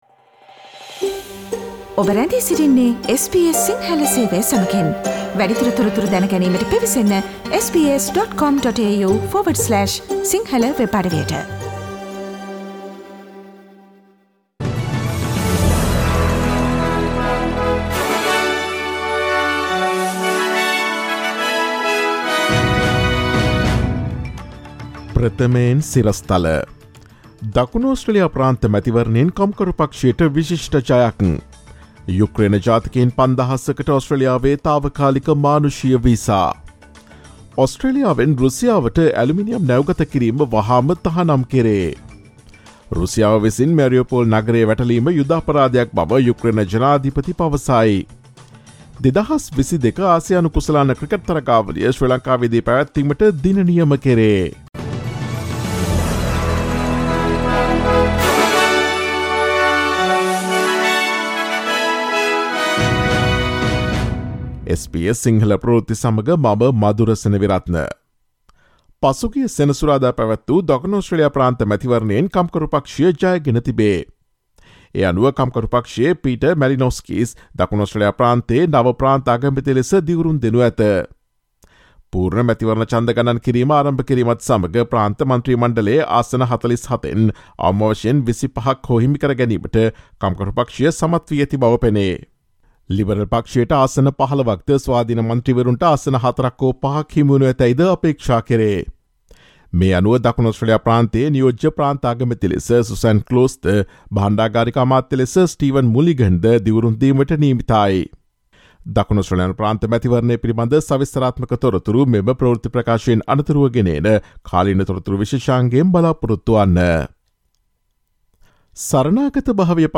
මාර්තු 21 දා SBS සිංහල ප්‍රවෘත්ති: යුක්රේන ජාතිකයින් 5000කට ඔස්ට්‍රේලියාවේ තාවකාලික මානුෂීය වීසා